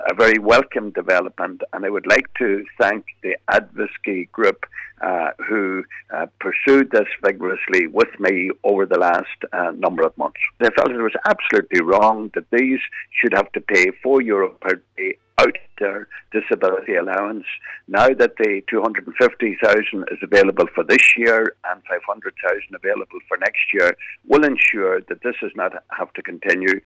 He says he is glad issues had been resolved after funding was delayed during the formation of the new Government: